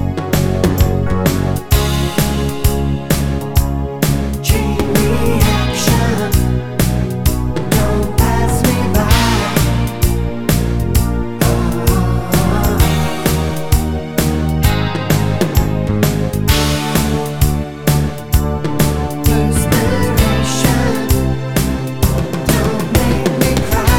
no Backing Vocals Soul / Motown 4:02 Buy £1.50